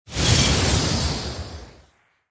soceress_skill_glacialspike.mp3